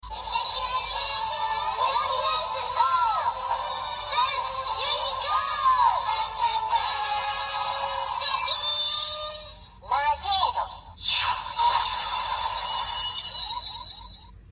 玛洁德饱藏音效.MP3